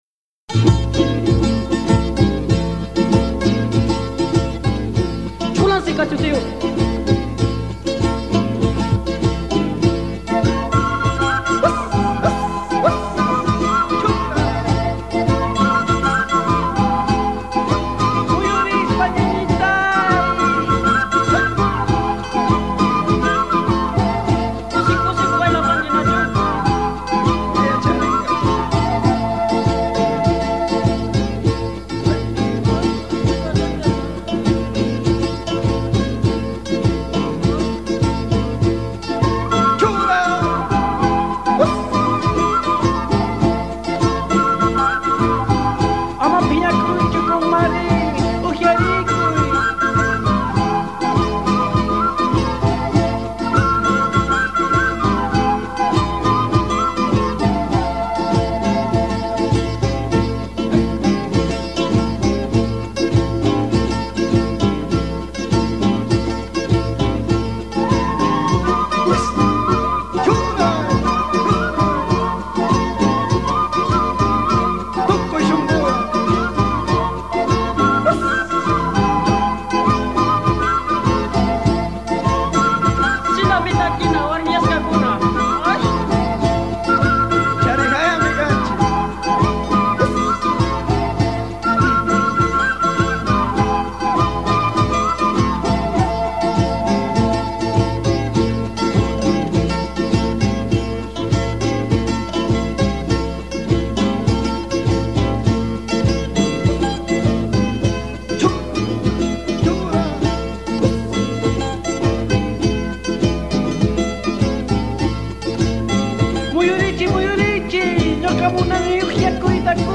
dentro del panorama de la musica andina del Ecuador.